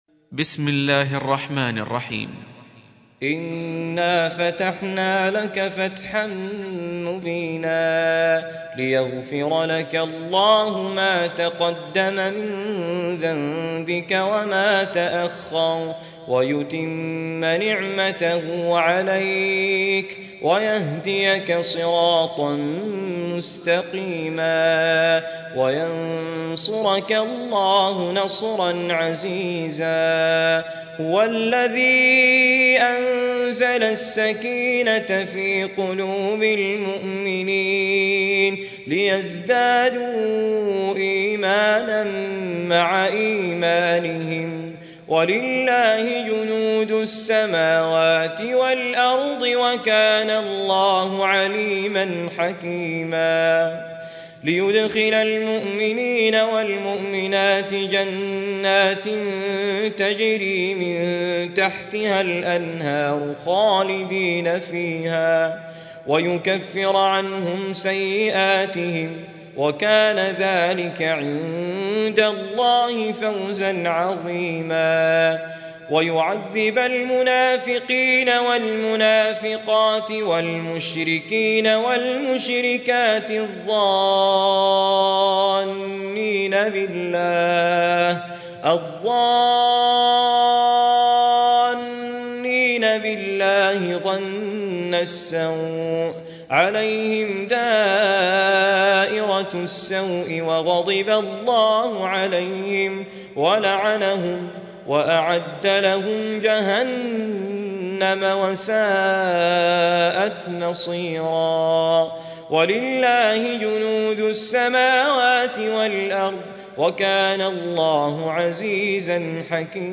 Surah Repeating تكرار السورة Download Surah حمّل السورة Reciting Murattalah Audio for 48. Surah Al-Fath سورة الفتح N.B *Surah Includes Al-Basmalah Reciters Sequents تتابع التلاوات Reciters Repeats تكرار التلاوات